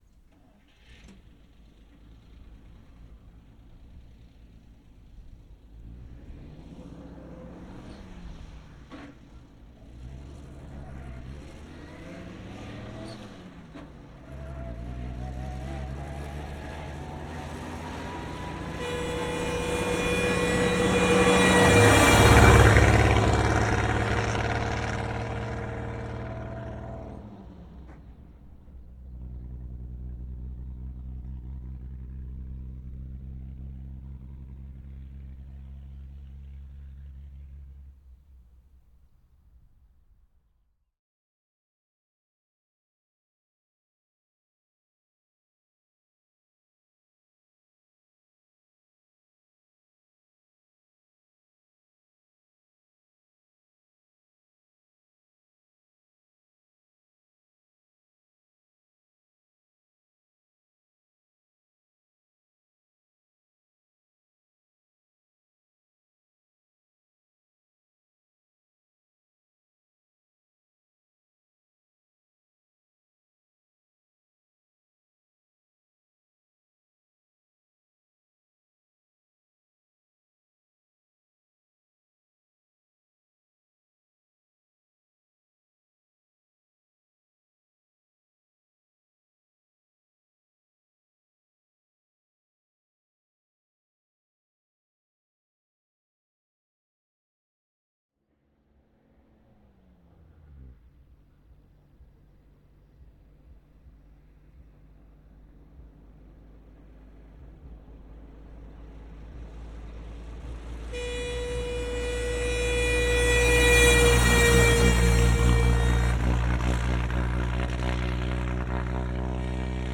opel_blitz_t14_ext_fast_bys_horn_position_b_MKH416.ogg